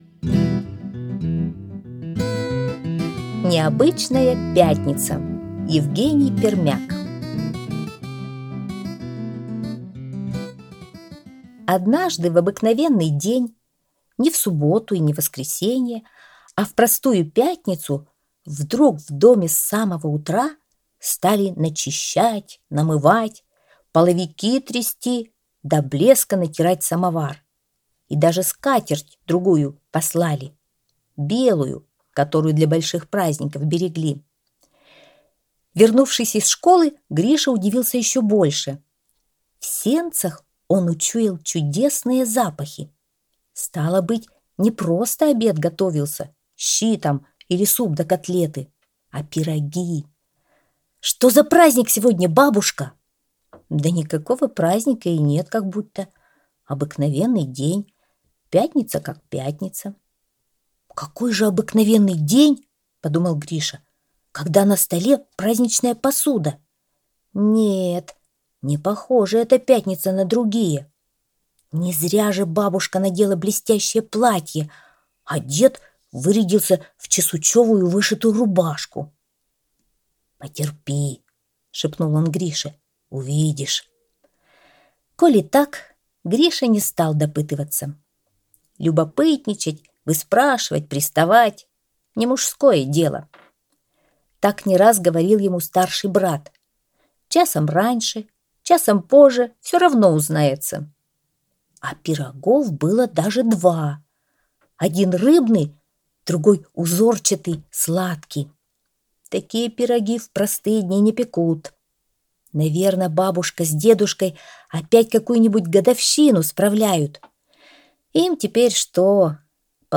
Аудиорассказ «Необычная пятница»